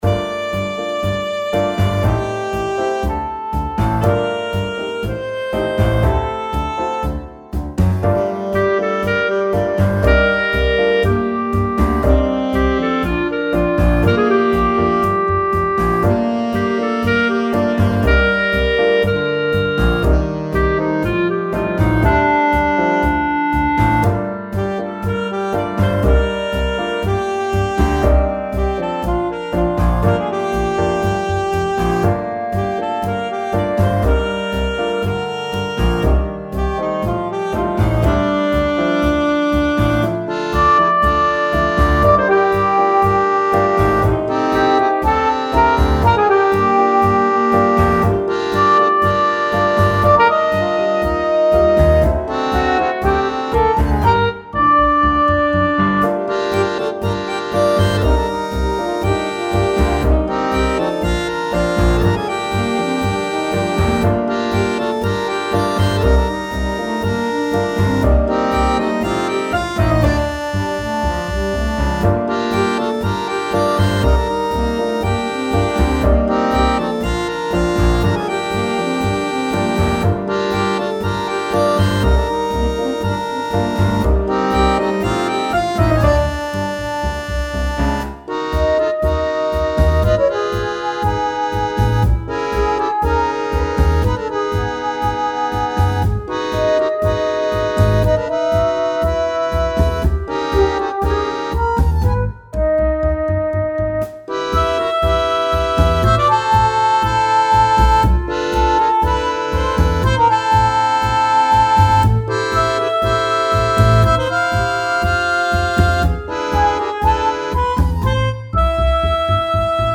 Zu guter Letzt, aus dem kommenden Mogimaus-Adventure „Baruch Farventas Tango Nr. 6“. Der ist freilich noch nicht fertig, so klingt er noch zu synthetisch: